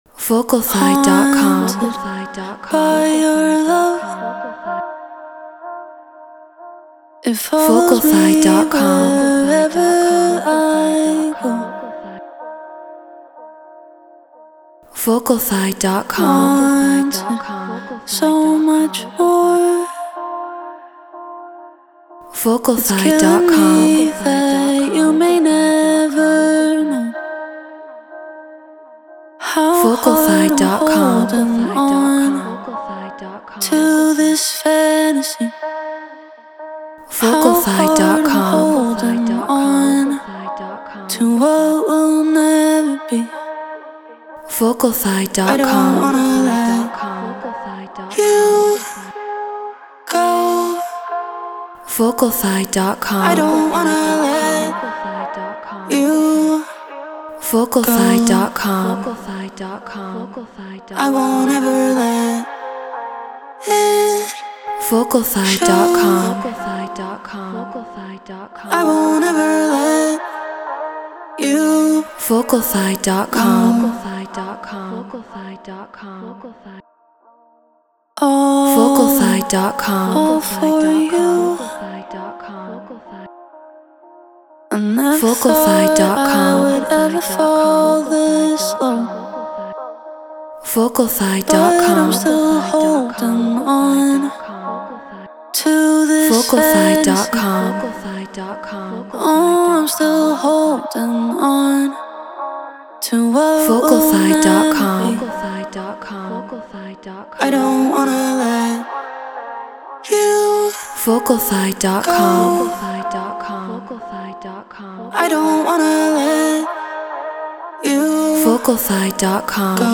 Deep House 124 BPM A#min
Shure SM7B Apollo Solo Logic Pro Treated Room